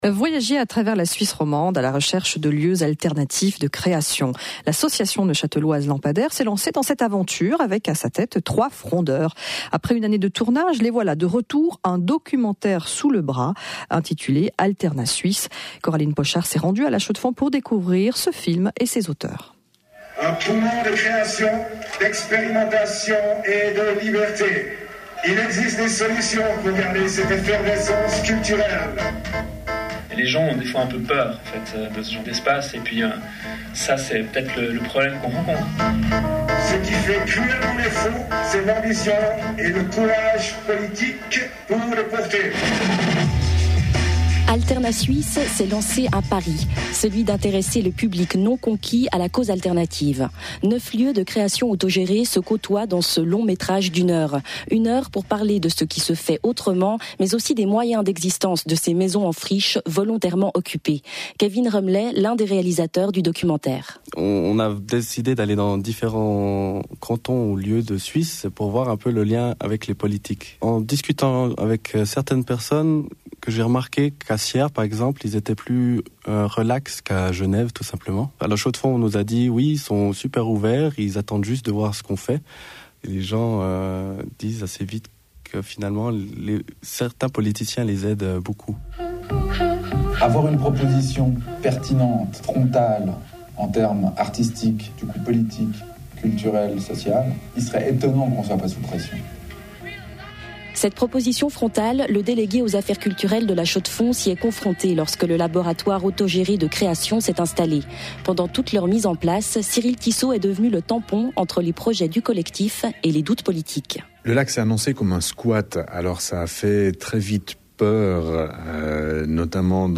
1) We were on RTS radio!